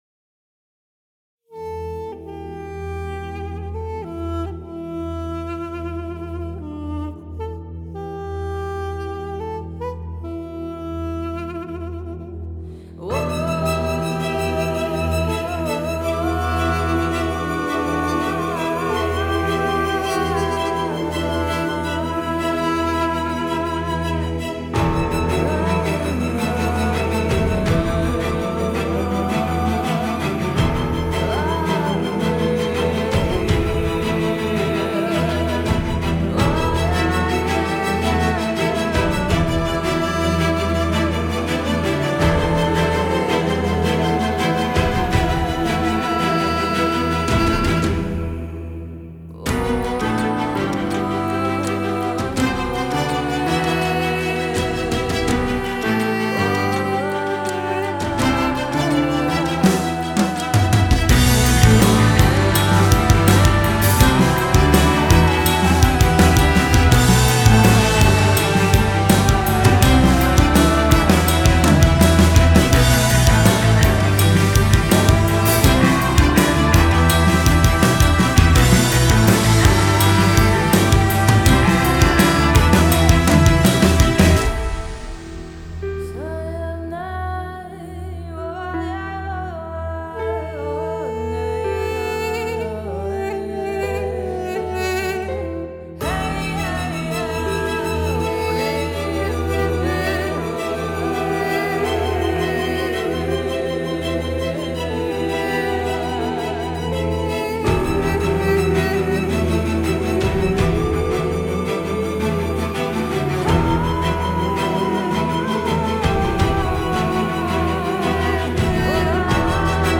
synth
вокал
bass
duduk